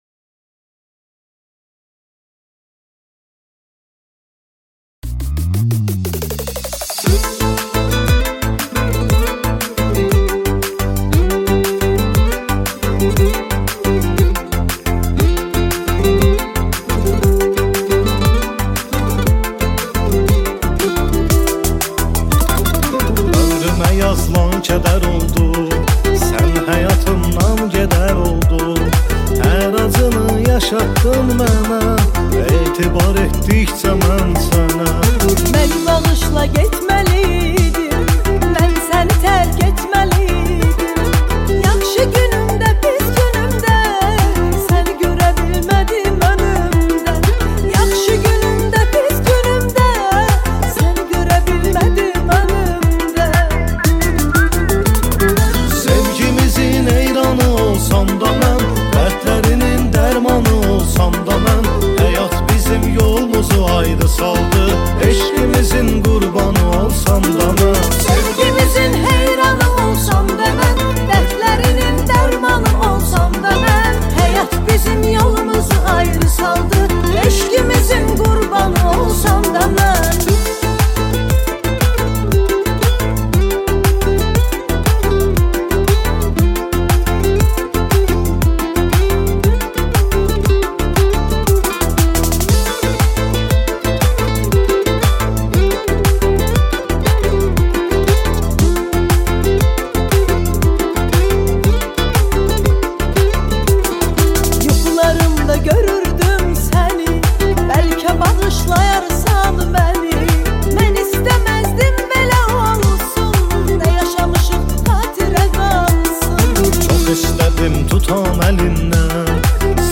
دانلود آهنگ آذربایجانی